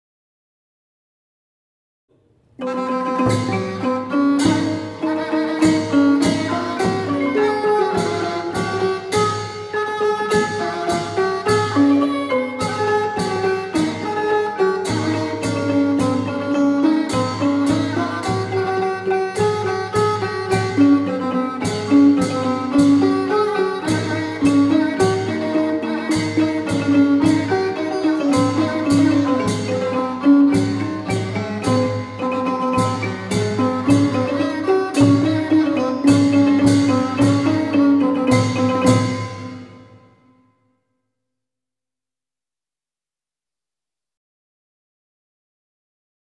เพลงมหาฤกษ์ (วงปี่พาทย์-ไม้นวม)